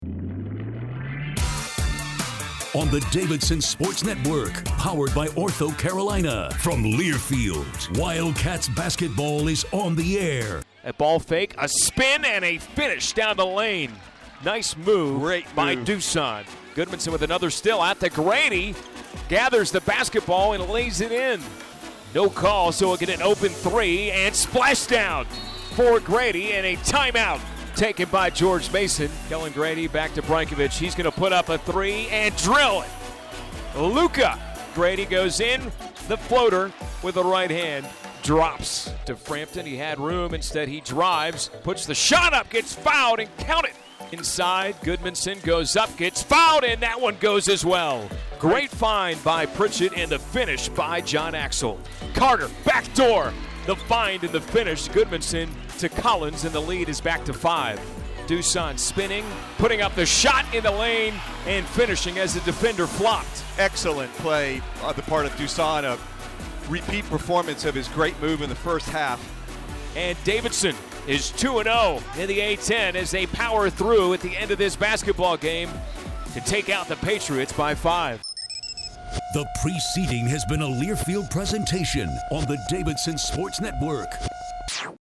Radio Highlights
MasonHighlights.mp3